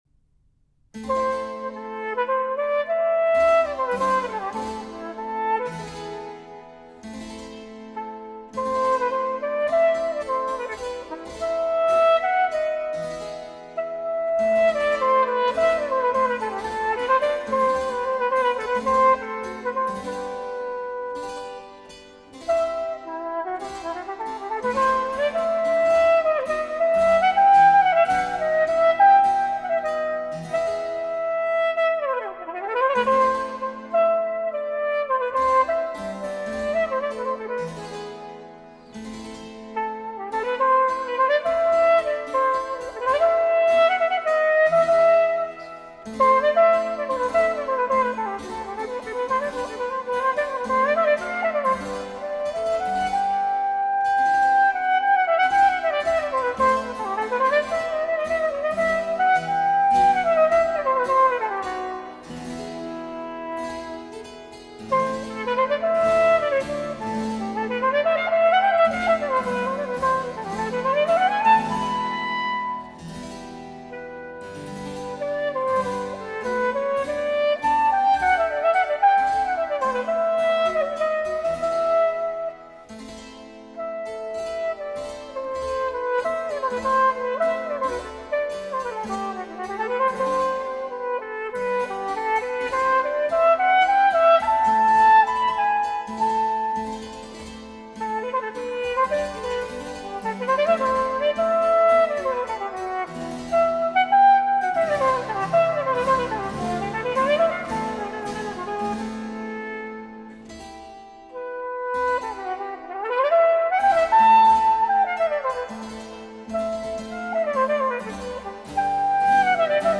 Ai microfoni uno dei migliori interpreti di cornetto al mondo
Incontro con il cornettista